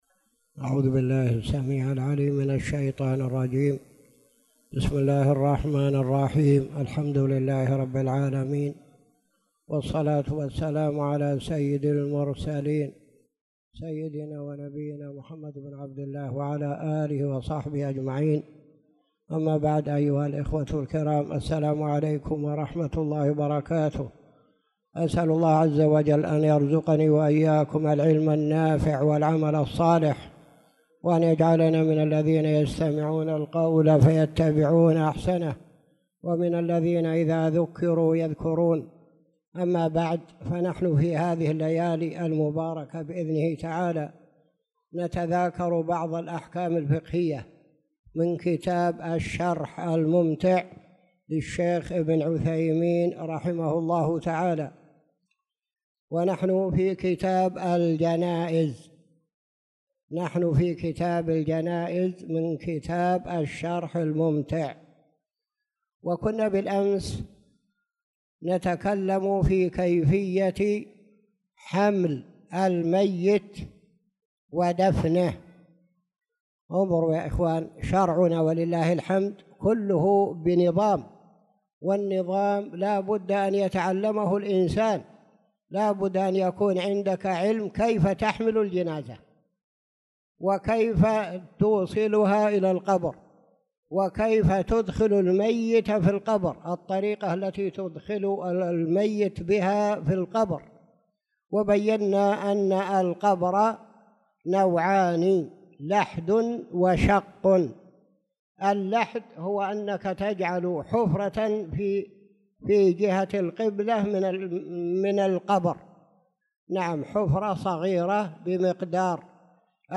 تاريخ النشر ١٣ ذو القعدة ١٤٣٧ هـ المكان: المسجد الحرام الشيخ